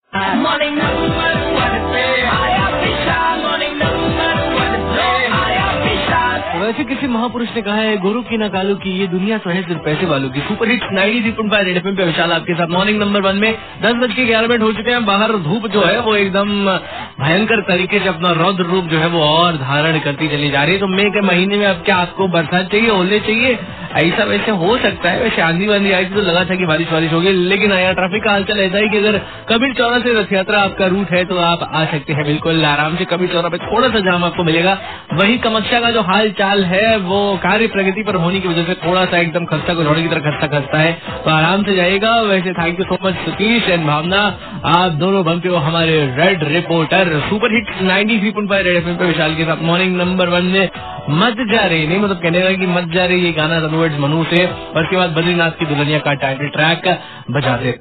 TRAFFIC UPDATE